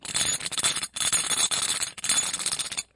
冲击、撞击、摩擦 工具 " 轻金属响声长
Tag: 工具 工具 崩溃 砰的一声 塑料 摩擦 金属 冲击